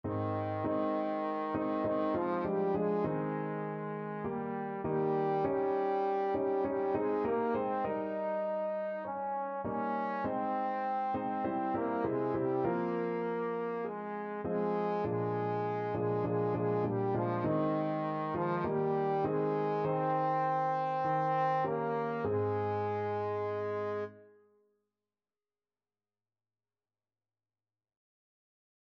Trombone
Ab major (Sounding Pitch) (View more Ab major Music for Trombone )
4/4 (View more 4/4 Music)
Classical (View more Classical Trombone Music)